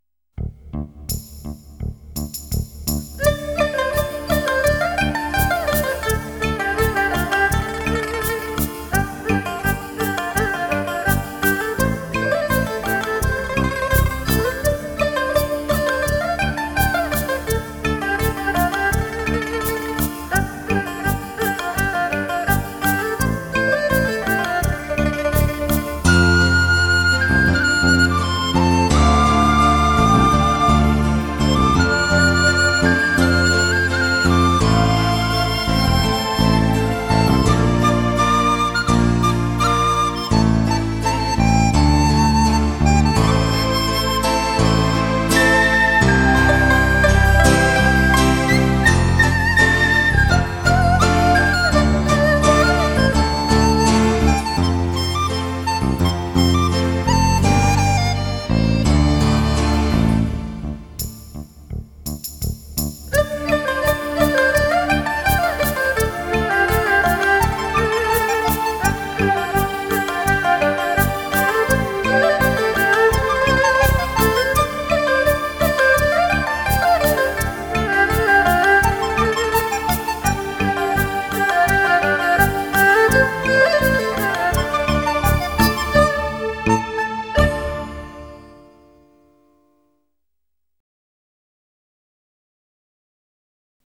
Ps：在线试听为压缩音质节选，体验无损音质请下载完整版 纯音乐，请欣赏